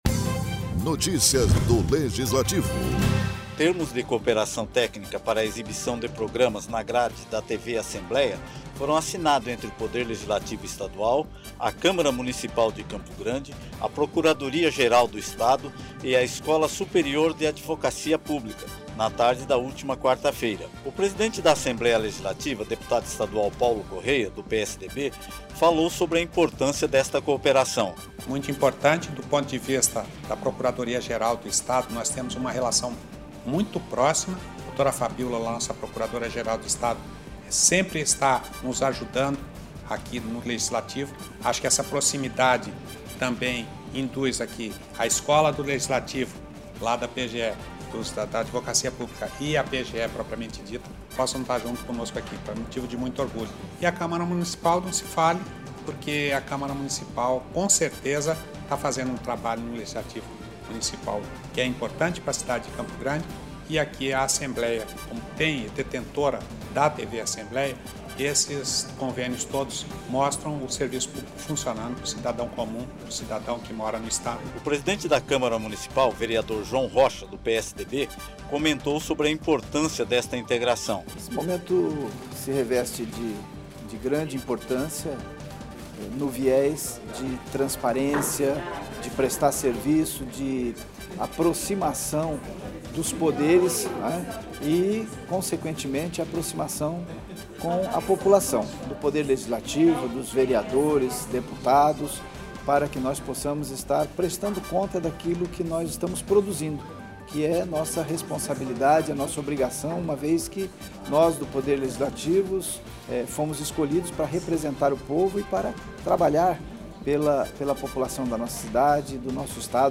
O presidente da Assembléia Legislativa deputado Paulo Correa do PSDB falou sobre a importância desta cooperação.